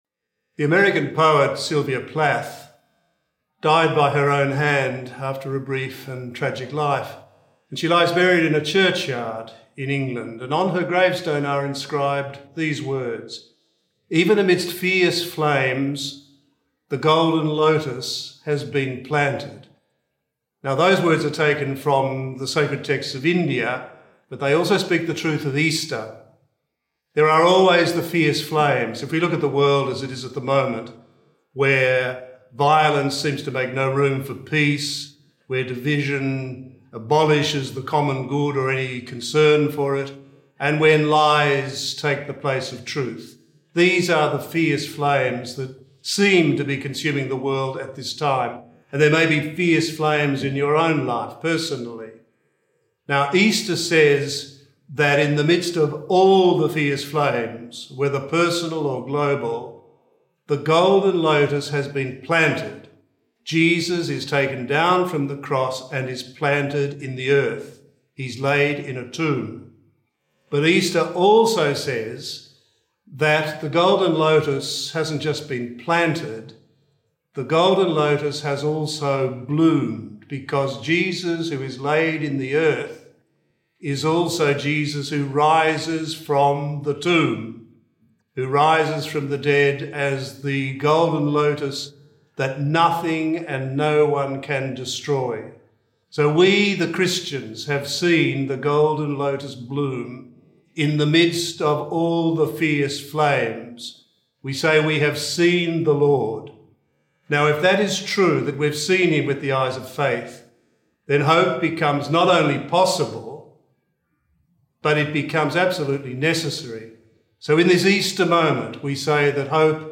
Easter Sunday - Two-Minute Homily: Archbishop Mark Coleridge
Two-Minute Homily by Archbishop Mark Coleridge for Easter Sunday 2025, Year C. "In this Easter moment we say that hope is our life, our life in Christ, and without that hope, there is only the death.